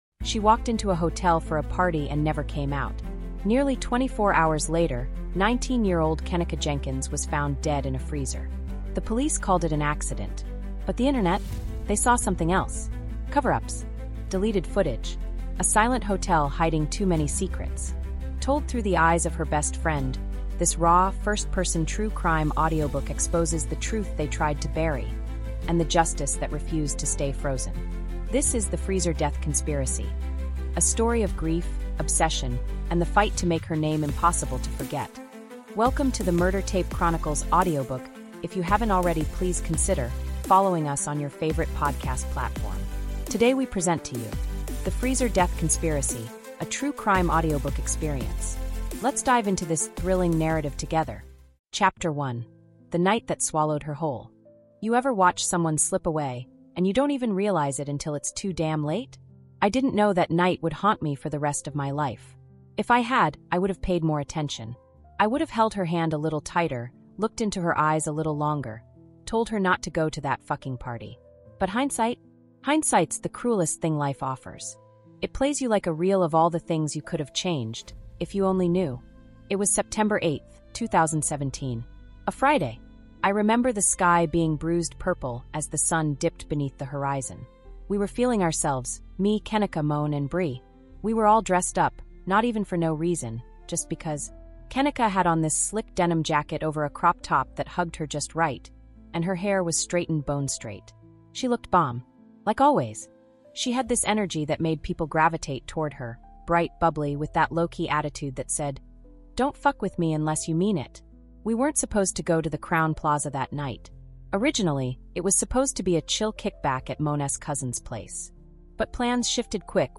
The Freezer Death Conspiracy | Unraveling a Mysterious Death | Audiobook